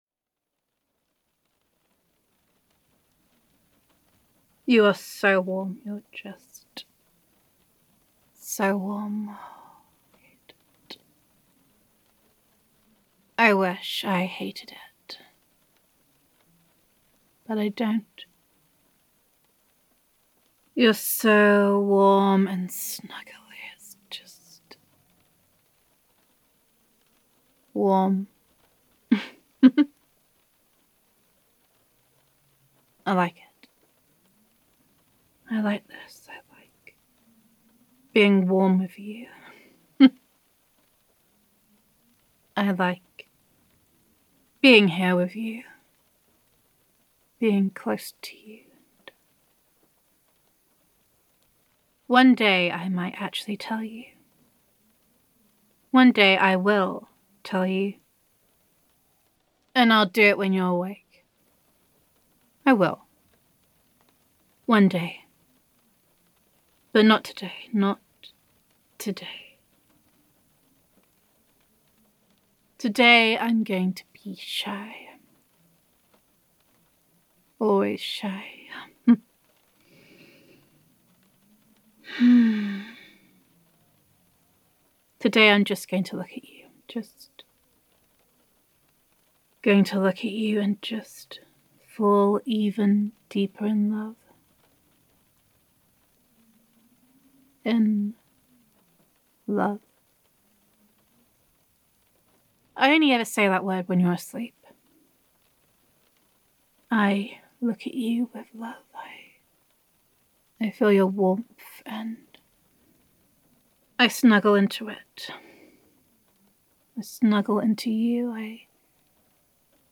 [F4A] You Heard Everything, Didn’t You? [Girlfriend Roleplay]